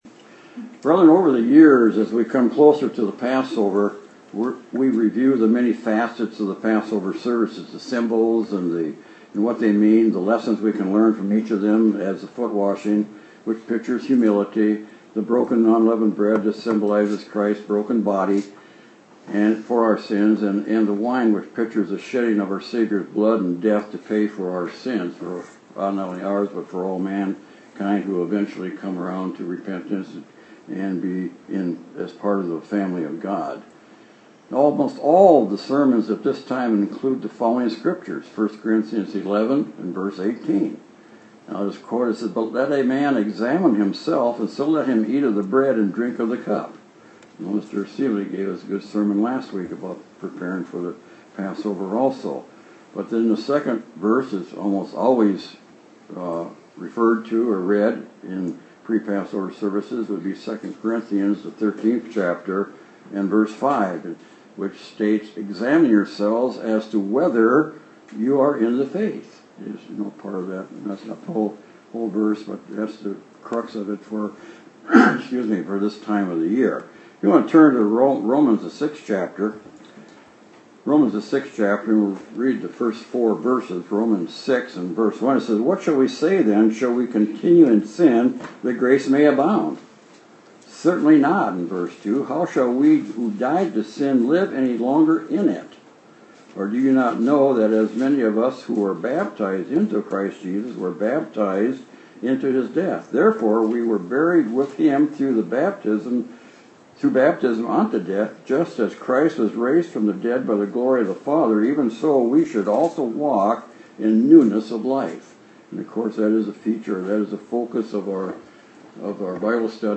Sermons
Given in Austin, TX